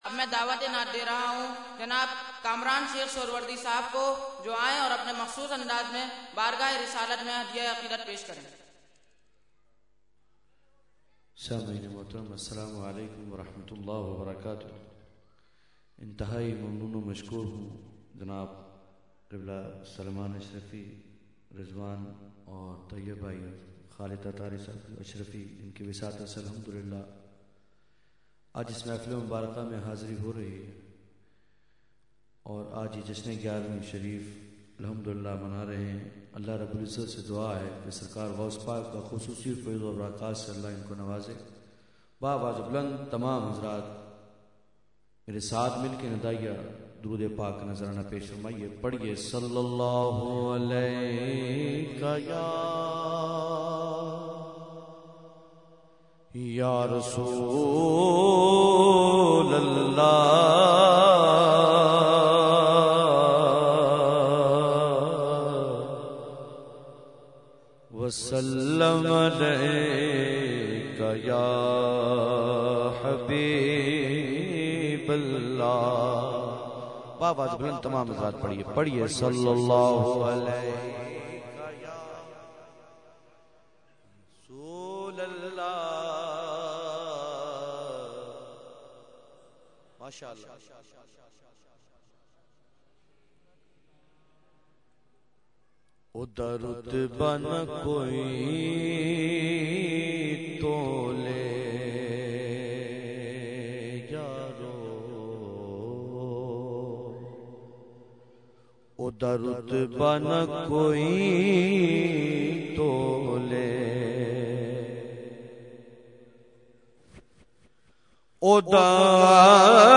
Category : Naat | Language : UrduEvent : Mehfil 11veen Nazimabad 23 March 2012